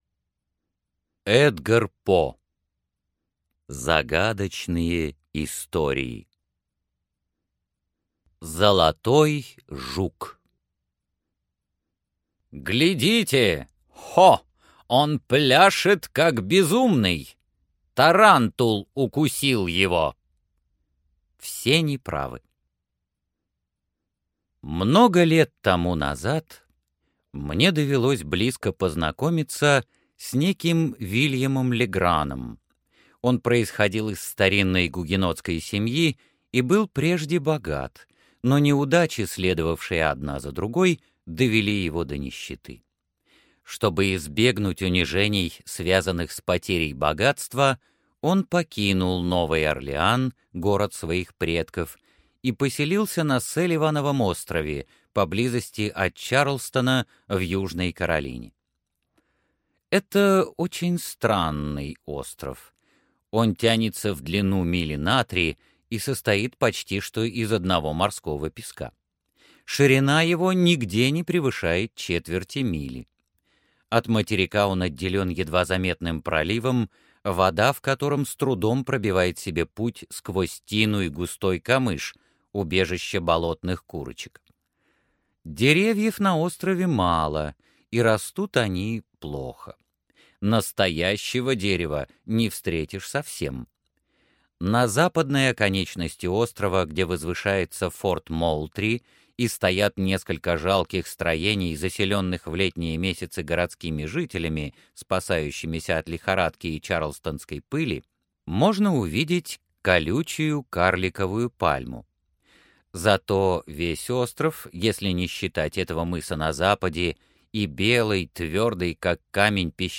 Золотой жук - аудио рассказ Эдгара По - слушать онлайн